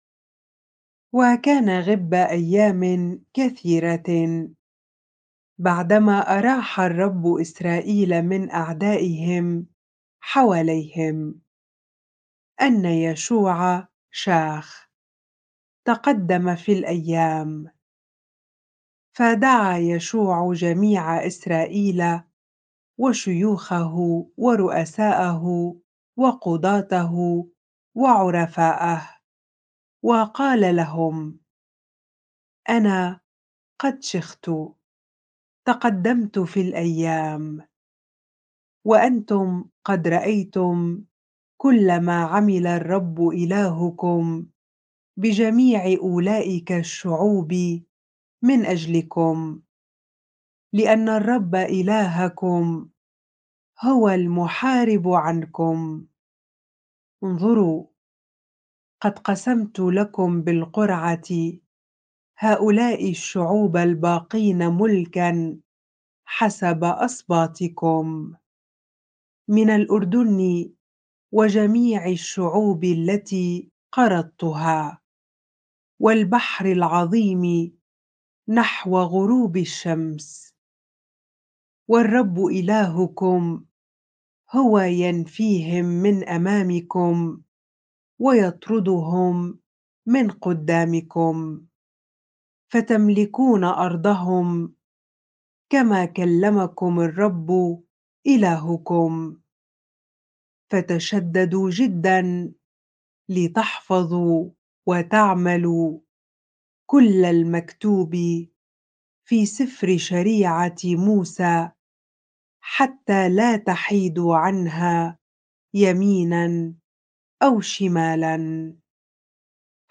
bible-reading-joshua 23 ar